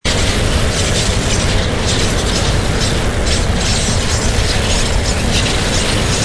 Молнии: